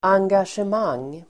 Uttal: [anggasjem'ang:]